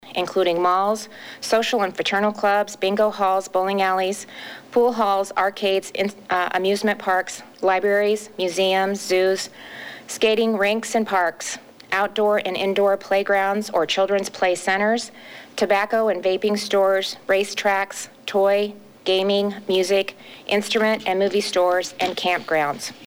Iowa Governor Kim Reynolds held her daily press briefing today earlier than normal, and will continue to do so each weekday at 11-a.m. During her update this morning, she said “Iowa’s fight against COVID-19 is real…and the last week has been especially hard.”